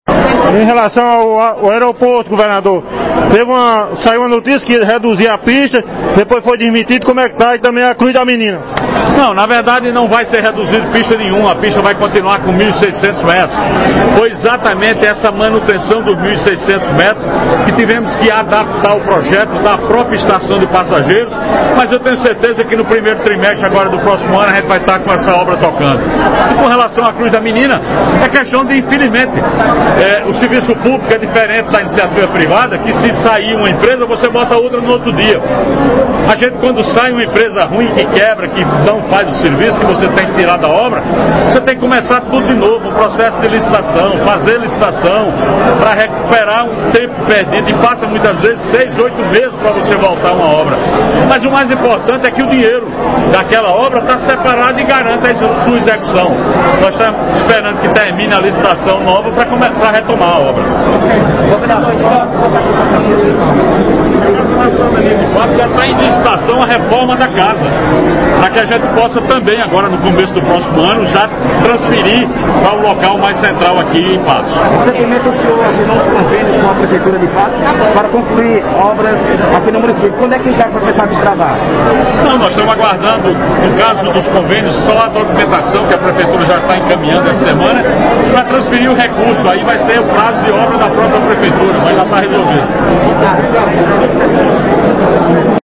Ouça abaixo a entrevista com o governador João Azevêdo: